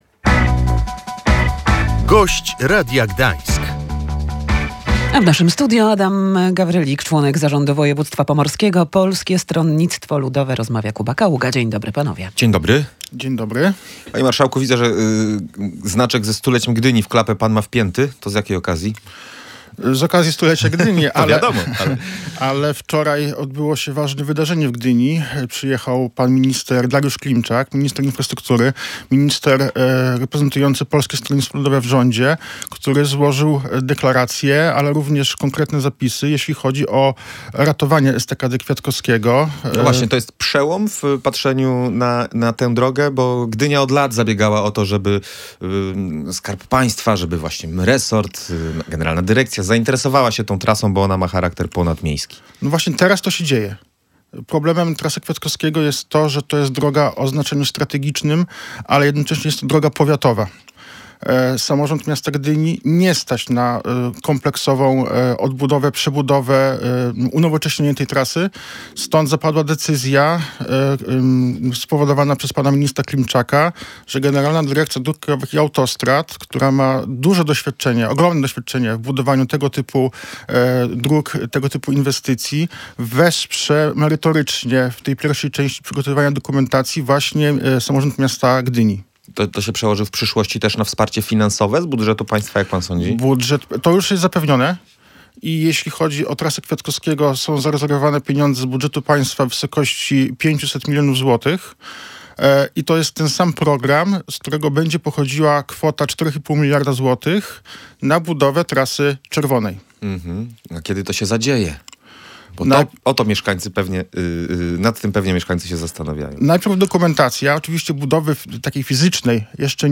Jak mówił w Radiu Gdańsk członek zarządu województwa Adam Gawrylik, Most Tczewski to istotny węzeł drogowy.
Gość Radia Gdańsk